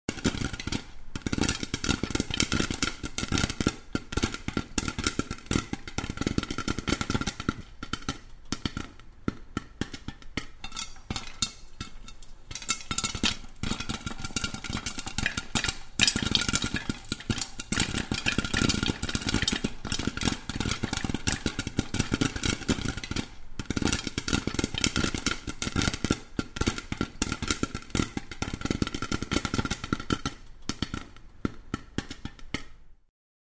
Звуки попкорна
Звуки приготовления попкорна — аудиозапись процесса готовки попкорна